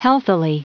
Prononciation du mot healthily en anglais (fichier audio)
healthily.wav